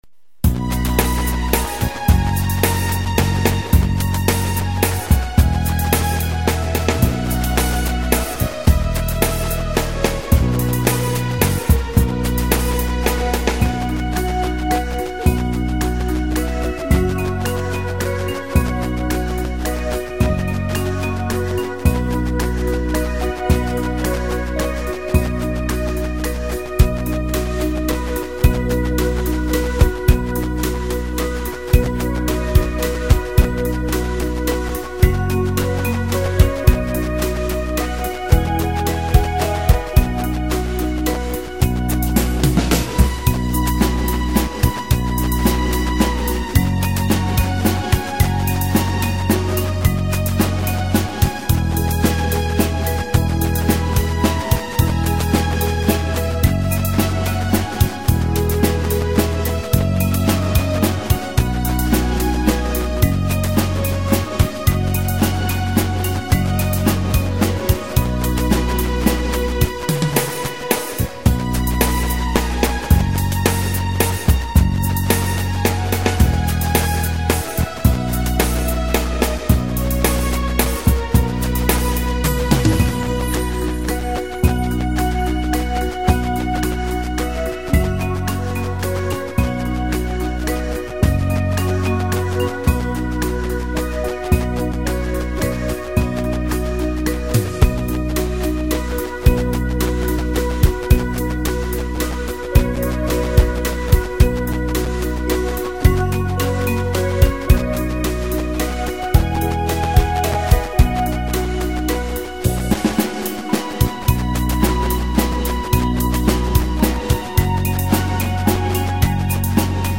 Nhạc không lời chủ đề quê hương- đất nước